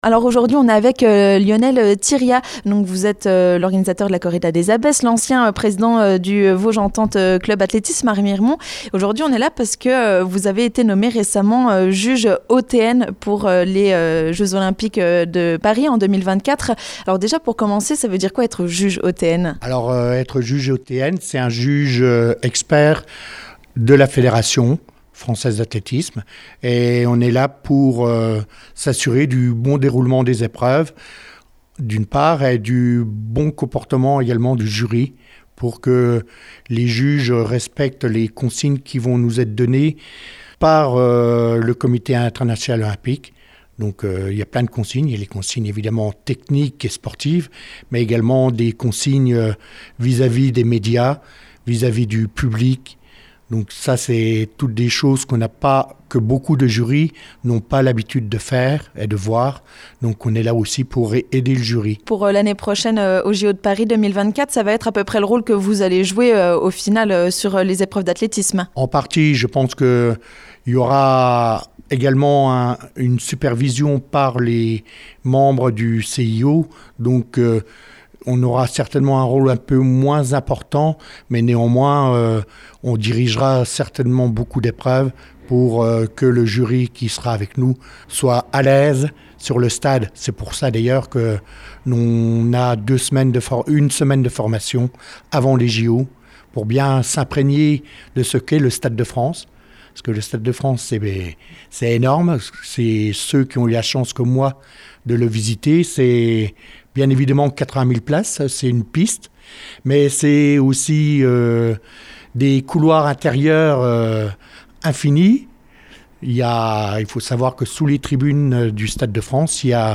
Nous sommes allés à sa rencontre pour lui poser quelques questions face à cette bonne nouvelle !